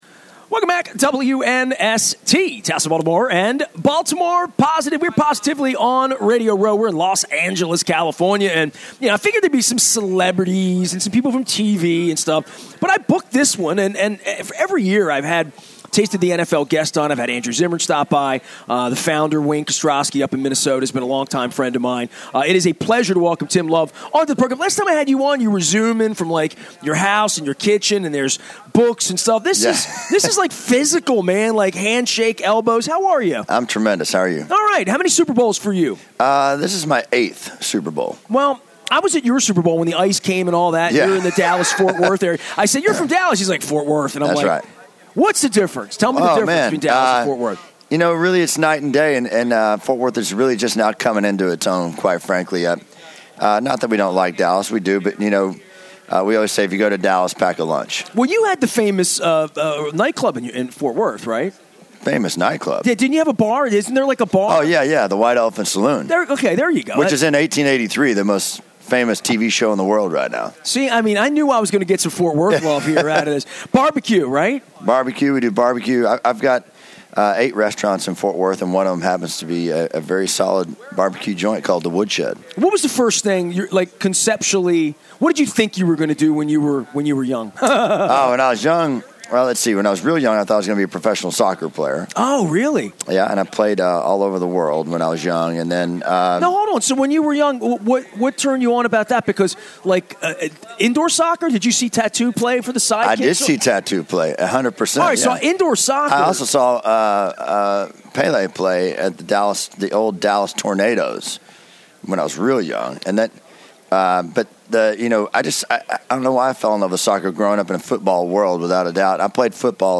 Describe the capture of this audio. on Radio Row